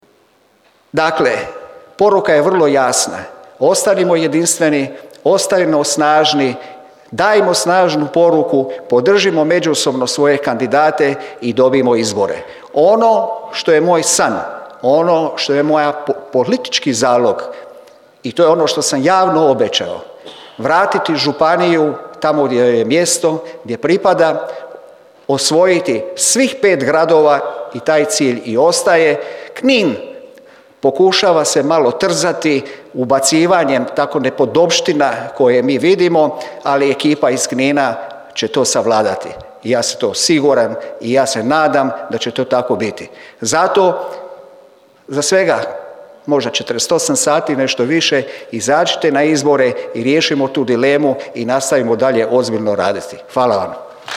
Drniški HDZ proslavio 35. obljetnicu osnutka te održao predizborni skup
Okupljenim stranačkim kolegama i simpatizerima HDZ-a obratio se i predsjednik ŽO HDZ-a Šibensko kninske županije dr. Željko Burić ujedno i kandidat za gradonačelnika Grada Šibenika za četvrti mandat: